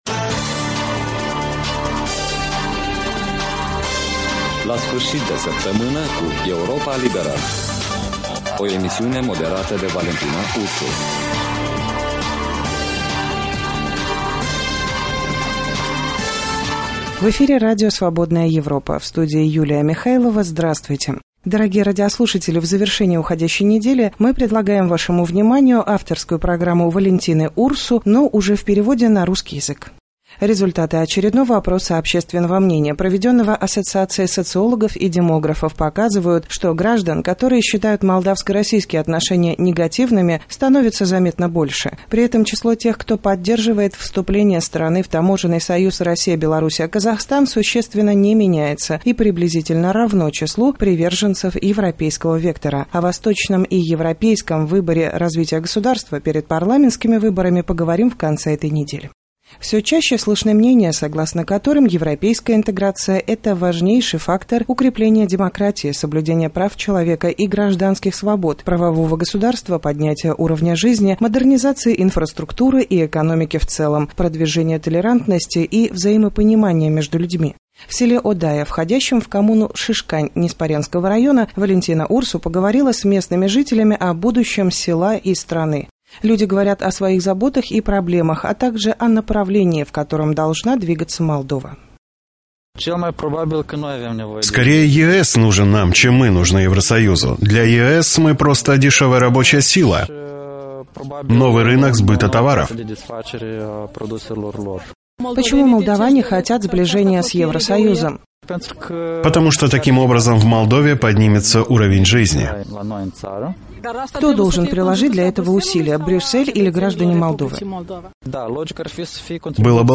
беседует с жителями села Одая Ниспоренского района